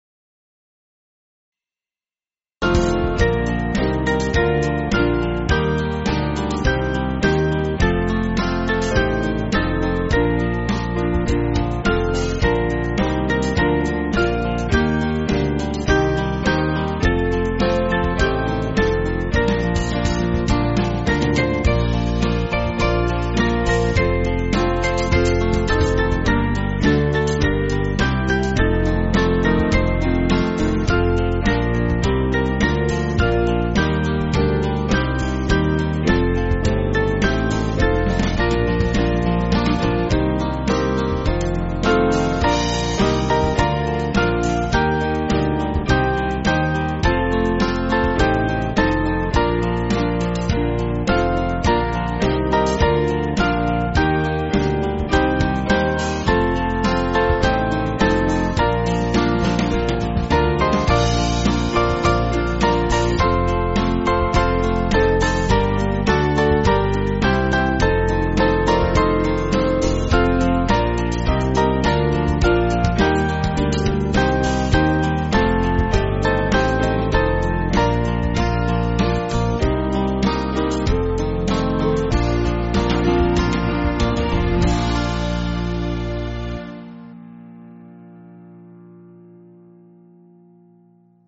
Small Band
(CM)   2/Bb